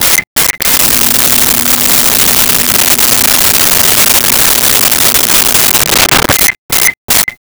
Microwave
Microwave.wav